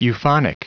Prononciation du mot euphonic en anglais (fichier audio)
Prononciation du mot : euphonic